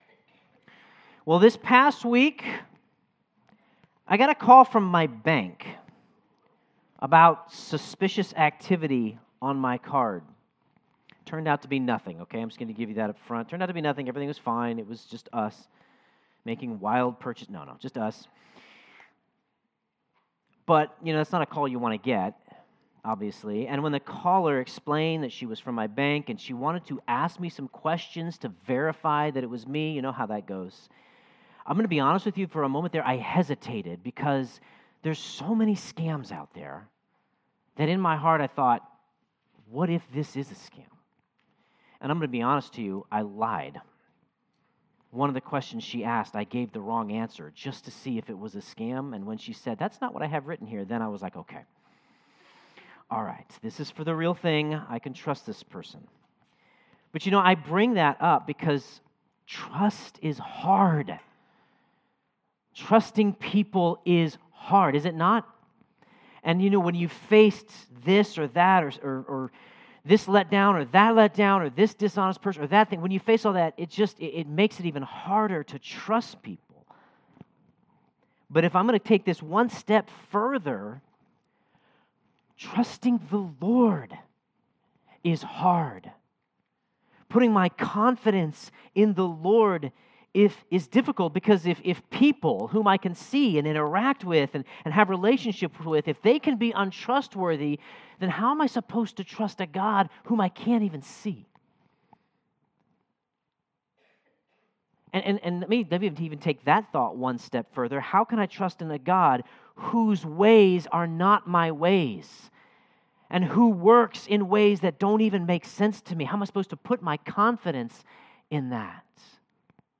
Sermon Notes How do we cultivate confidence in the Lord? We cultivate that confidence by remembering and continuing to pursue His presence.